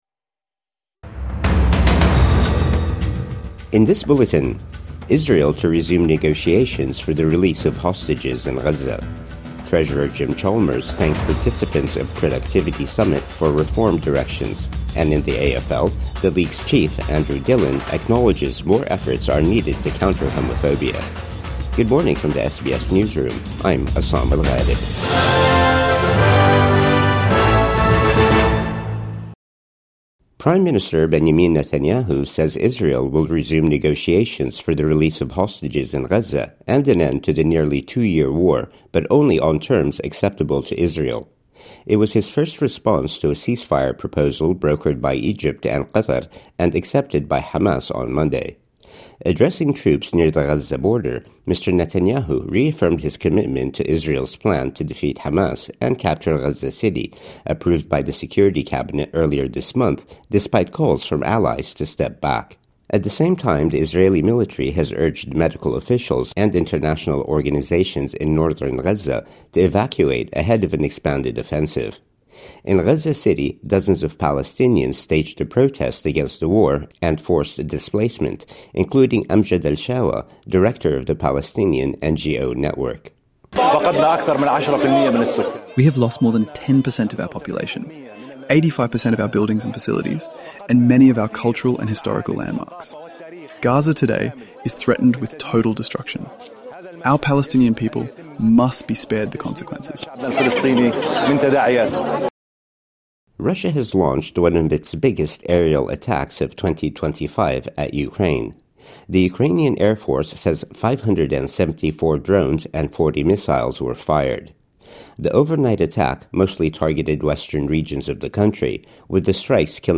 Israel to resume negotiations for the release of hostages in Gaza | Morning News Bulletin 22 August 2025